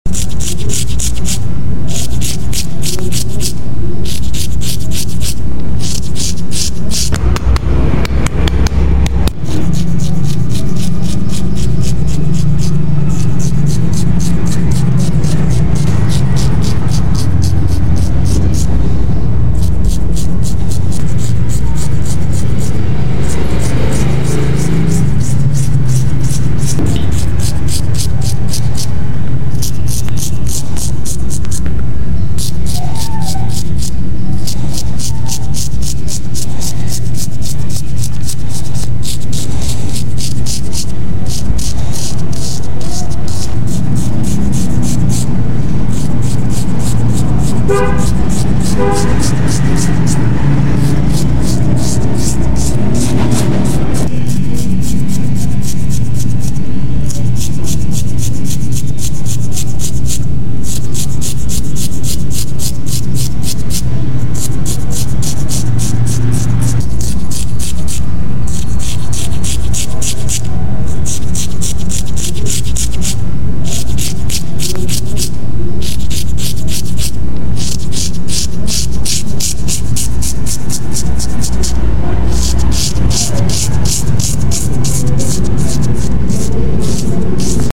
ASMR Fast Head Shave without sound effects free download
ASMR Fast Head Shave without Water 🚿 | Oddly Satisfying Sounds